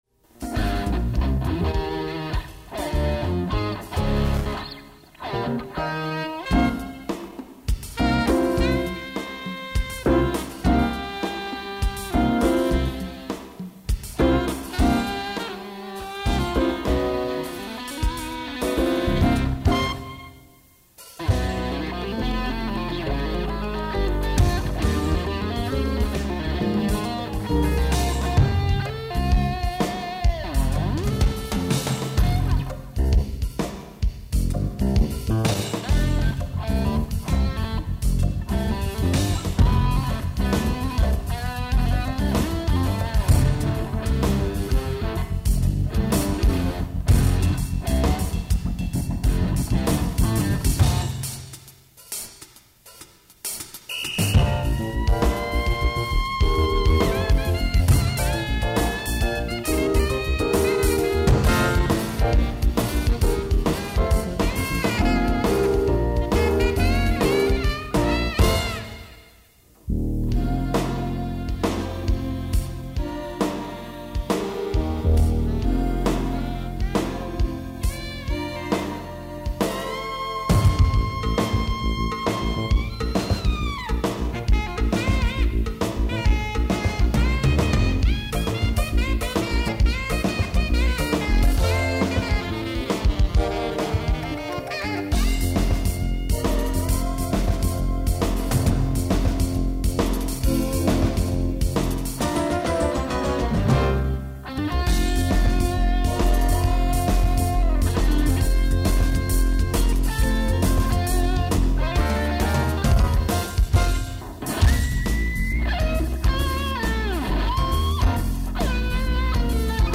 guitar
saxophone
Recorded at 270 Comm Ave. practice rooms, Boston, MA-1991